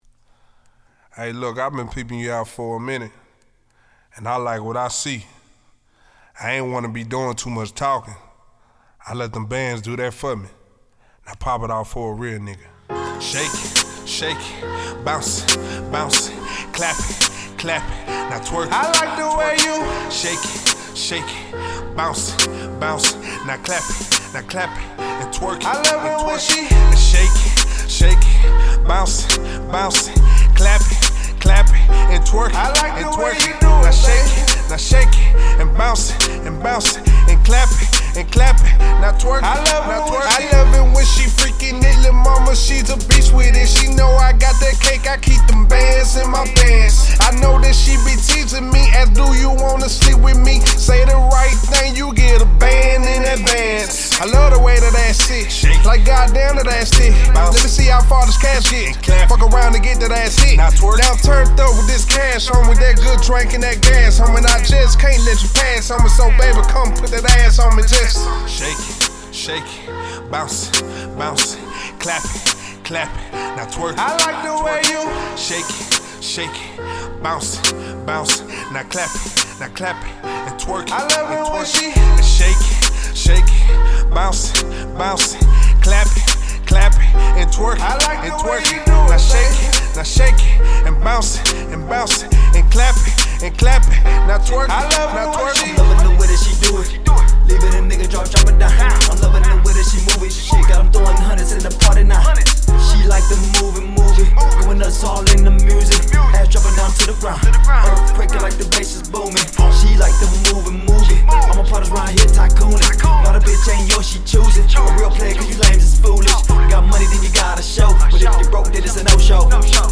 Hiphop
new club track